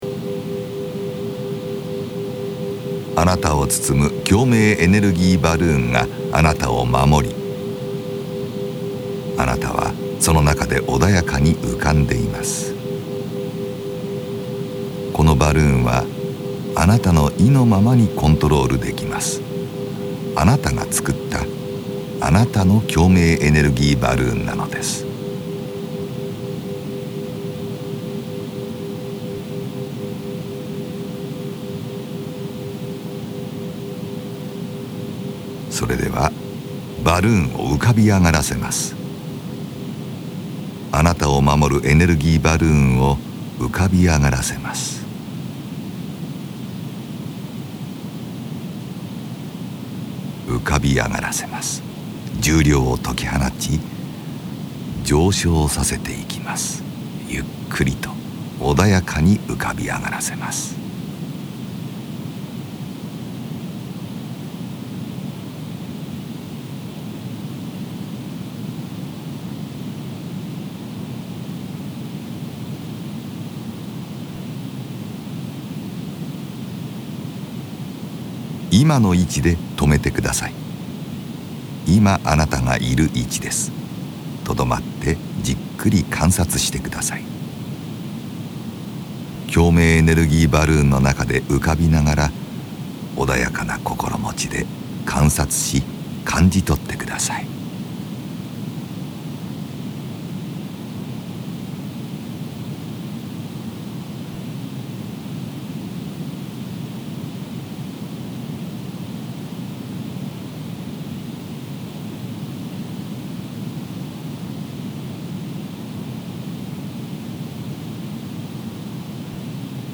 このオーディオ・ガイダンスのプロセスは、何層もの複雑なオーディオ信号を組み合わせることによって共鳴現象を起こし、特殊な脳波を発生させて、意識を特別な状態へと誘導するものです。
ヘミシンク信号に加えて、ミュージックや音声ガイダンス、あるいはかすかな音響効果などが組み合わされて、その効果はさらに高められます。